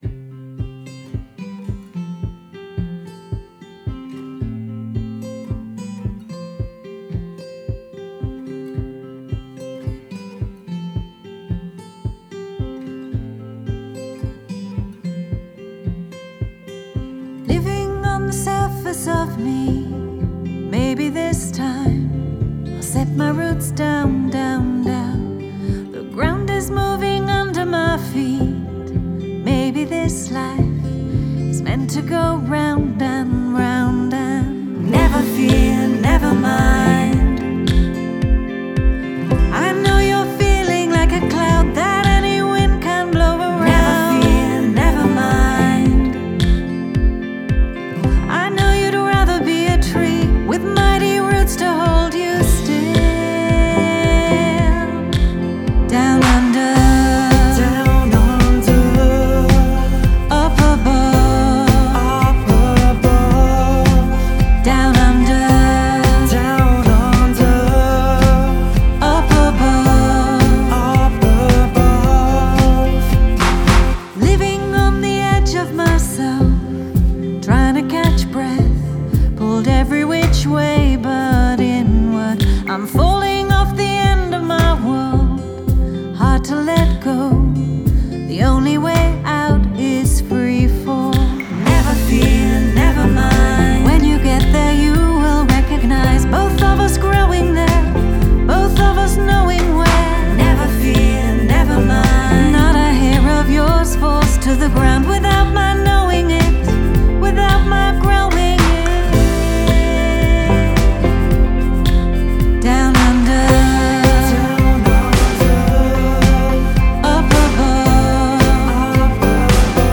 Composition